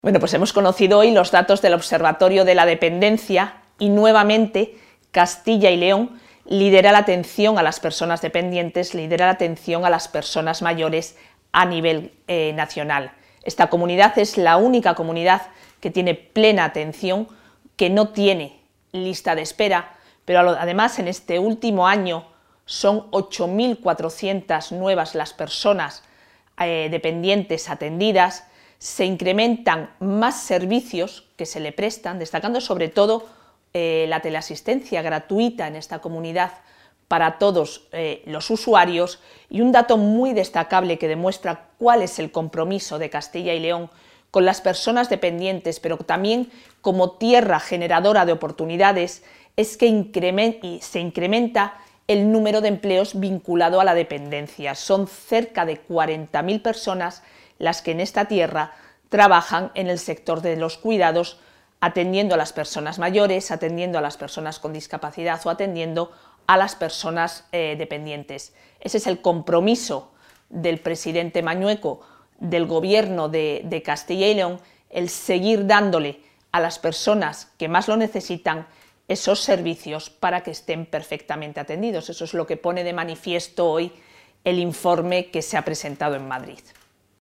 Valoración de la consejera.